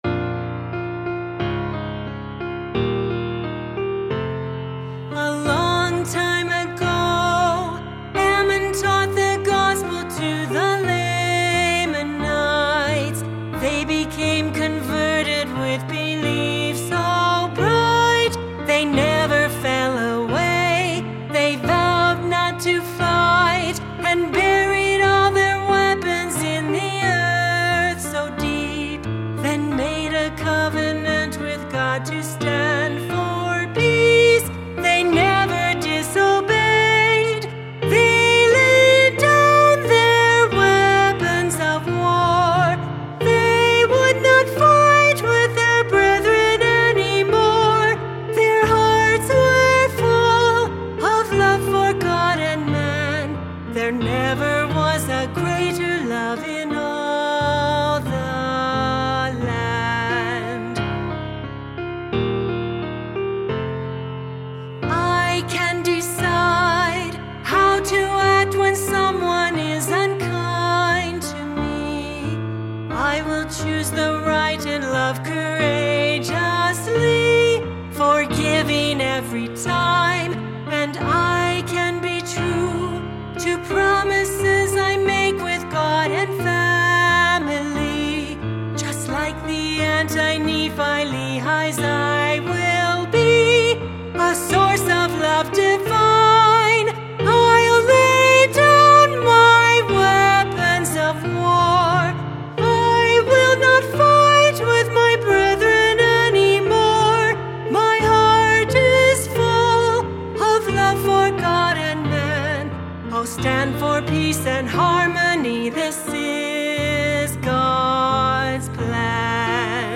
Children’s Song with Piano
lively children’s song